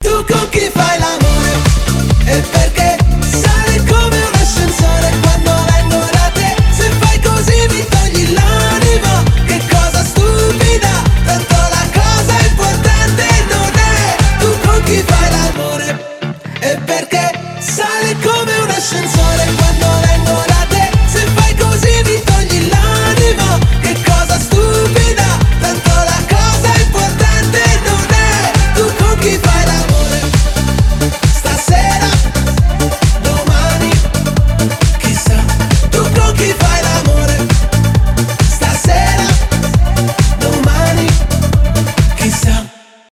pop rock , танцевальные , зажигательные